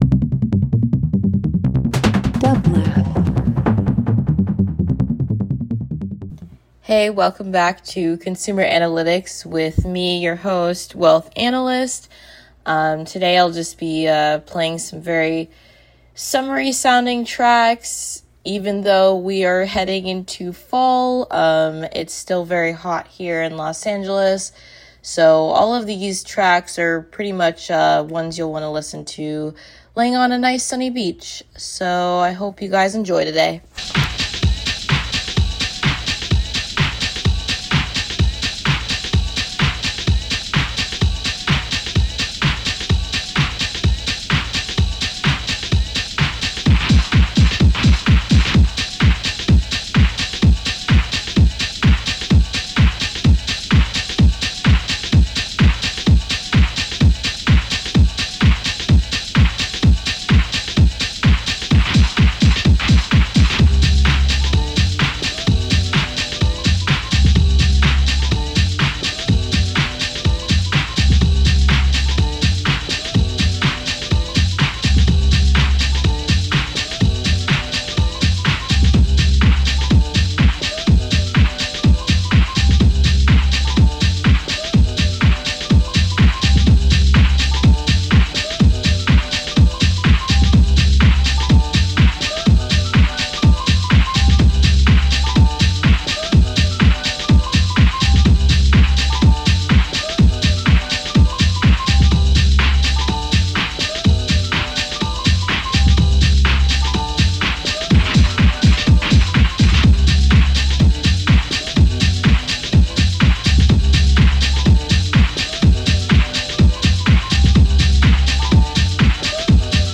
Dance Electronic House Reggae/Dub